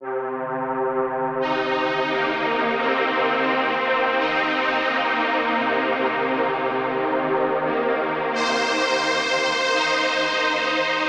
Orc Strings 01.wav